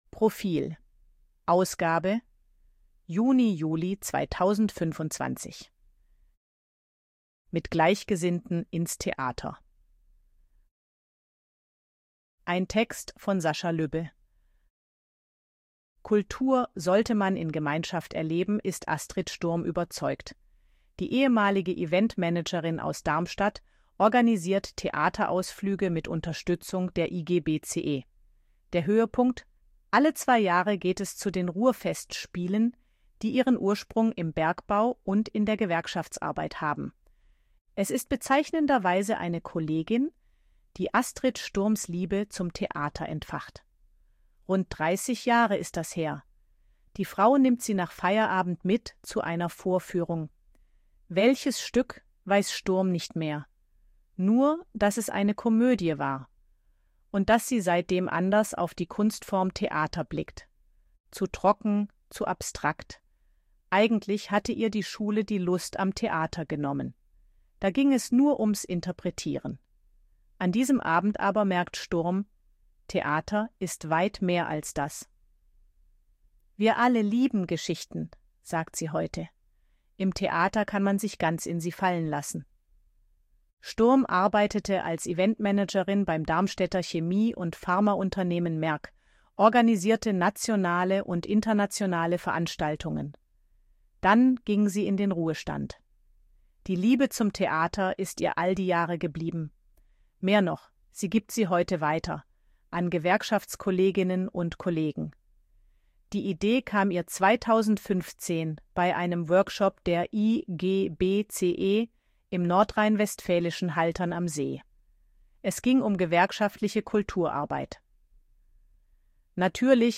ElevenLabs_KI_Stimme_Frau_Portraet.ogg